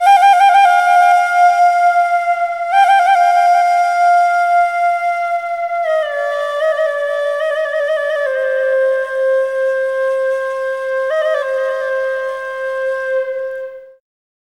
EASTFLUTE5-L.wav